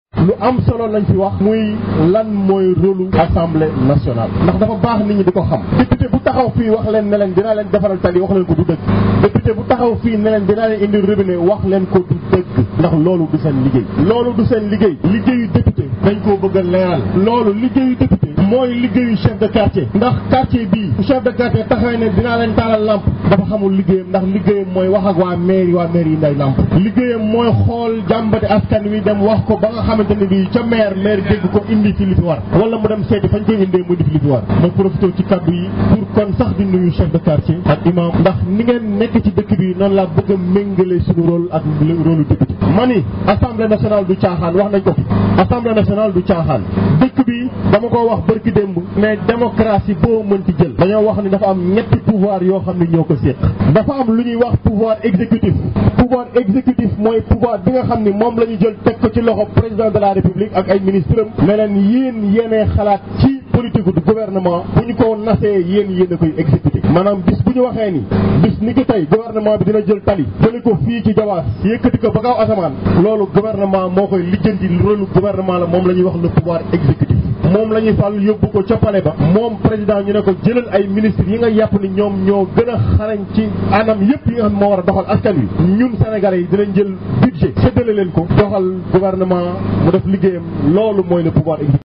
L inter coalition Wallu yewu Askanwi a lancé sa campagne dans le département de Thies à Notto Diobass .Une occasion saisie par le coordonnateur du comité électoral départemental Biram Souleye Diop d’annoncer que l’objectif de la coalition est de faire une rupture au niveau de l’assemblée nationale (Audio)